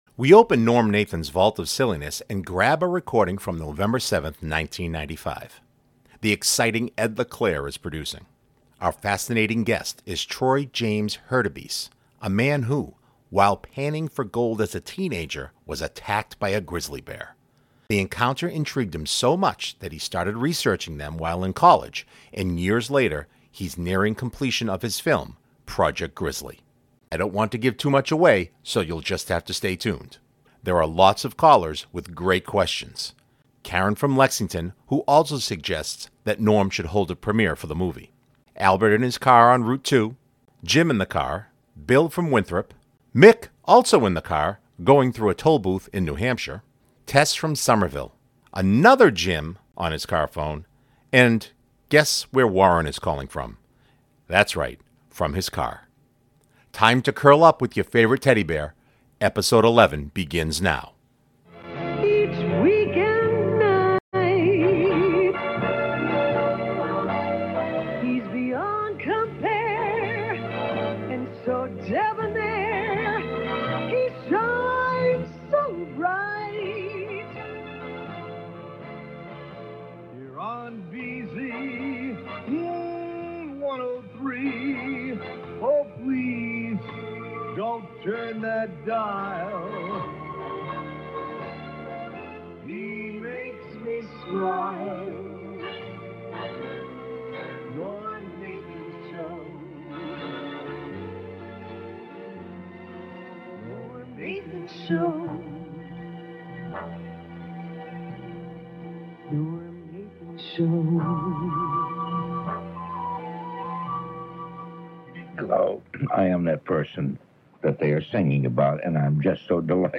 Callers with great questions: